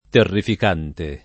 terrifico [ terr & fiko ]